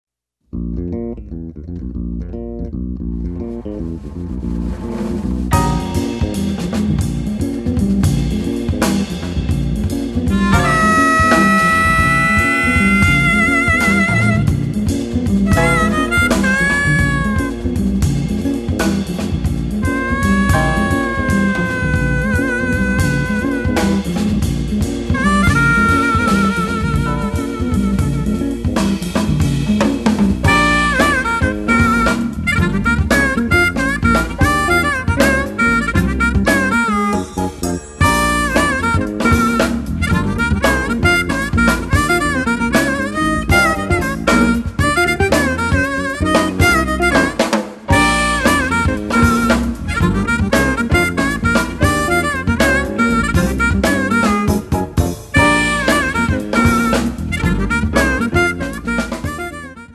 armonica
basso
chitarra
piano
batteria